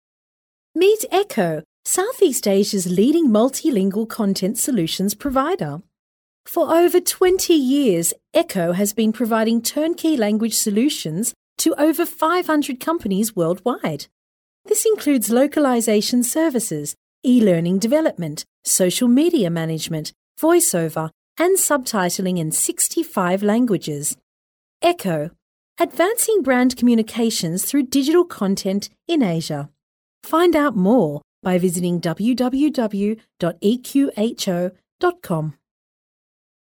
EN (AUS) Female 03896
COMMERCIAL